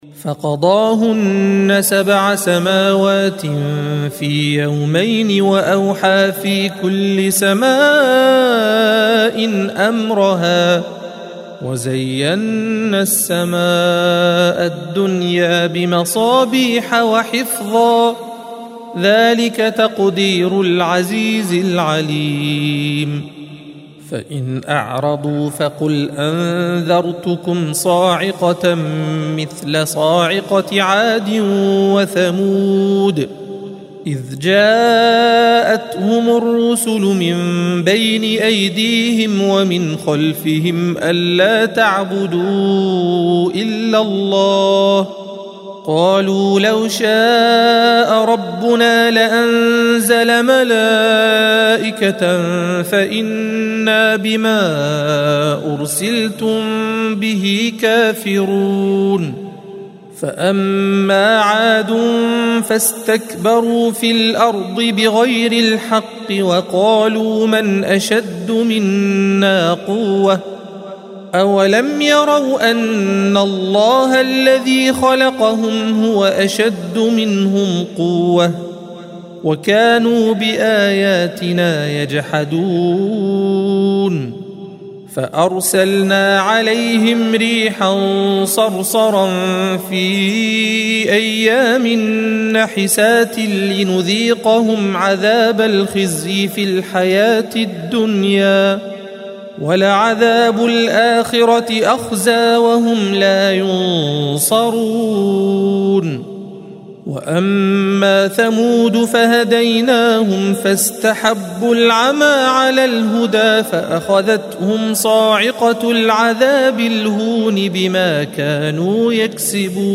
الصفحة 478 - القارئ